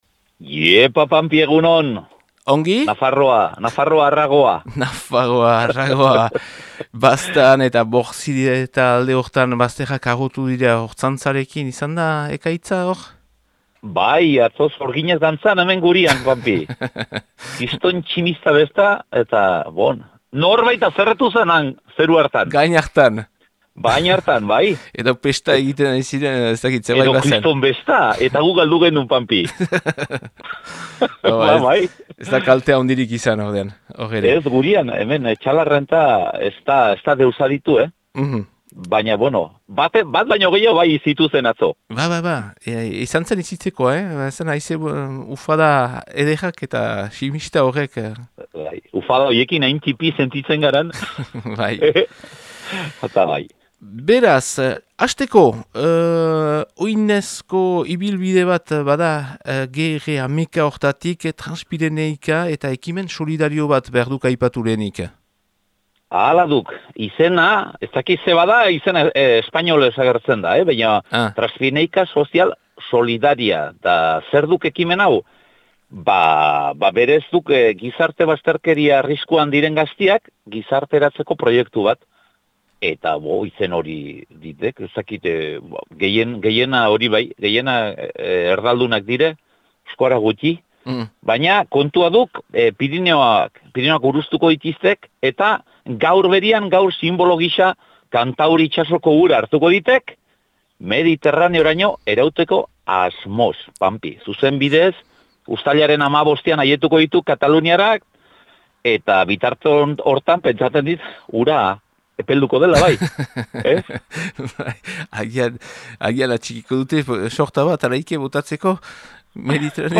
BERRIKETARIAK | ETXALAR